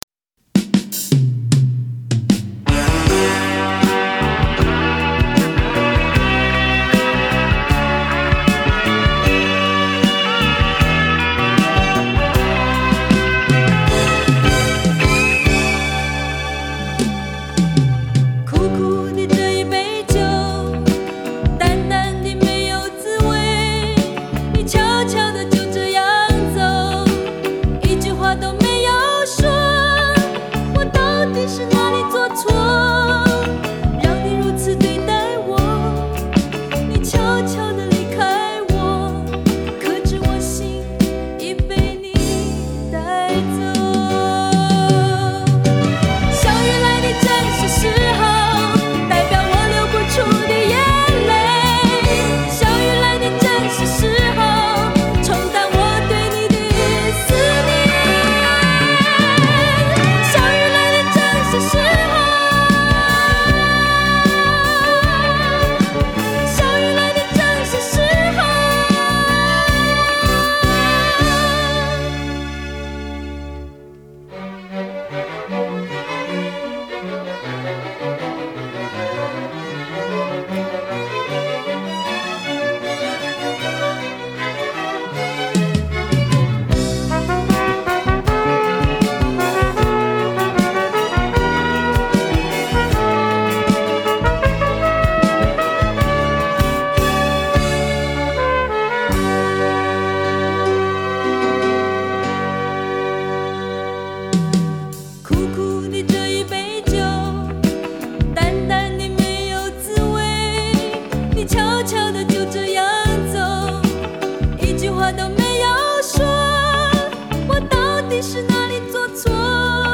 她拔尖又略带民谣味的嗓音才传遍大街小巷
玲珑剔透、清亮率直